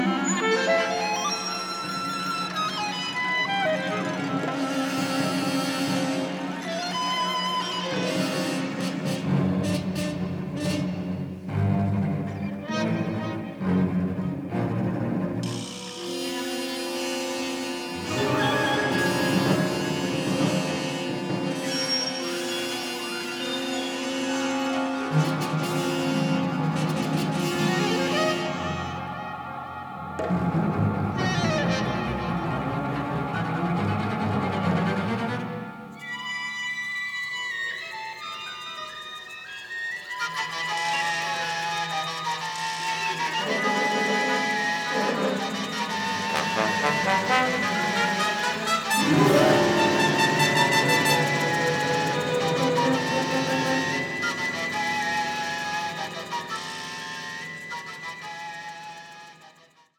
Sound quality is excellent.